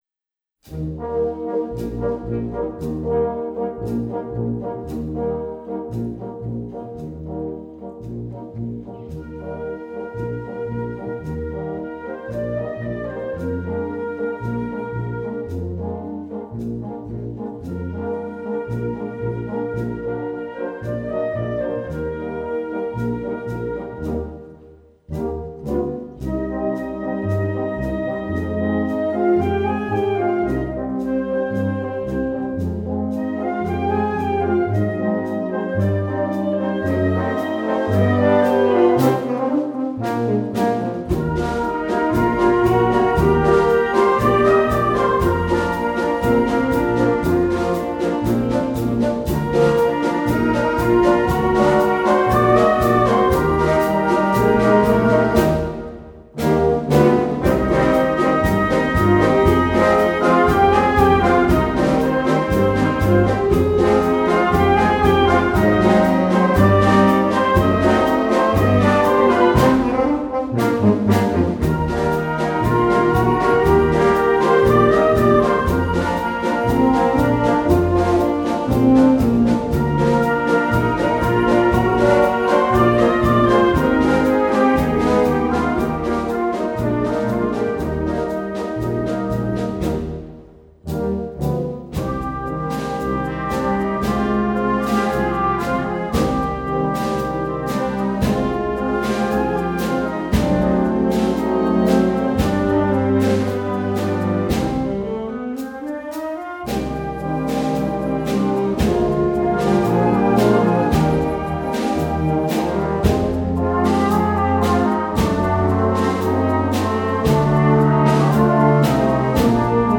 Gattung: Filmmusik für Blasorchester
Besetzung: Blasorchester